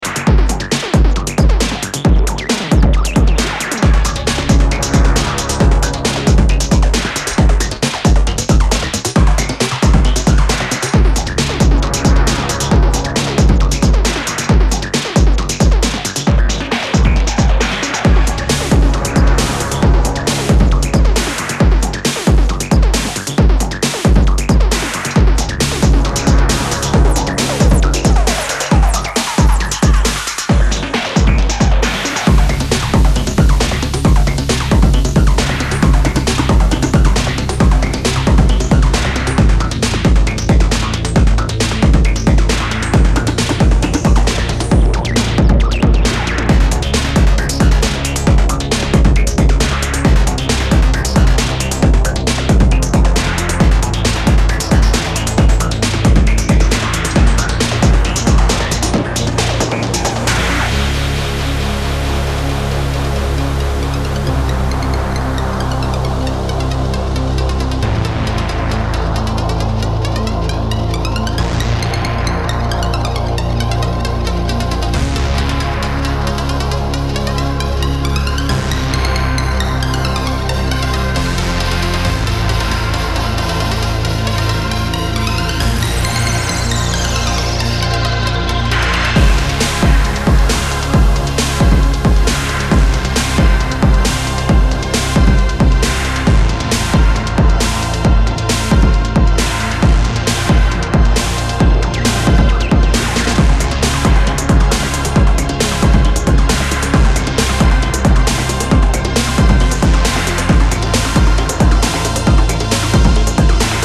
Four solid electro cuts dominate